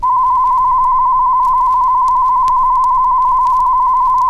Stereo_Test_Tone_01.wav